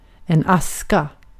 Uttal
Uttal : IPA: /ˈàska/ Ordet hittades på dessa språk: svenska Översättning Substantiv 1. kül Artikel: en .